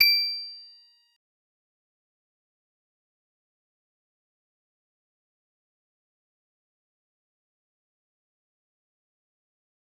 G_Musicbox-D8-mf.wav